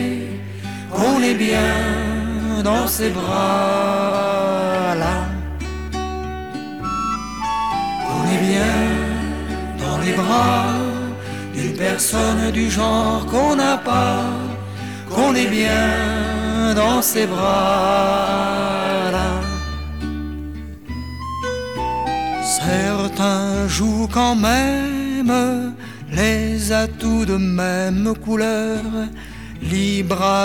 0 => "Chanson francophone"